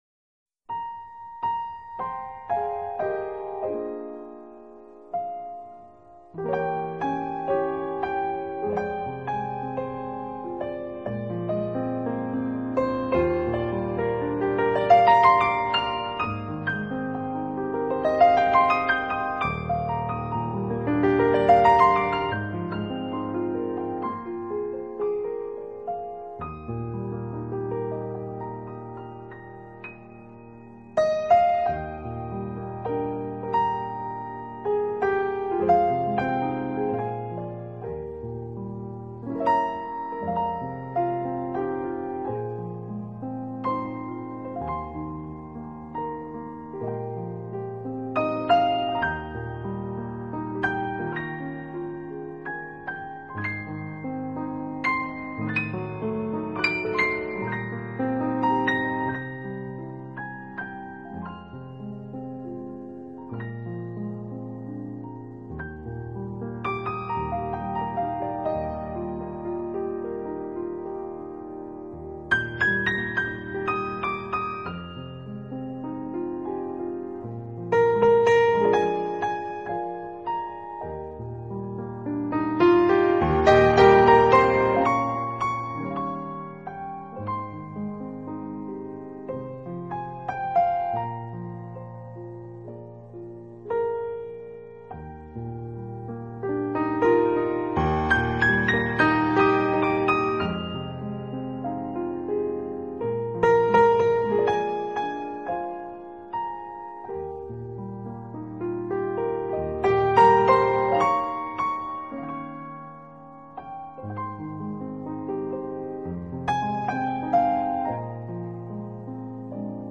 【钢琴纯乐】
音乐风格：New Age
這这张专辑的曲目全是以慢色调搭配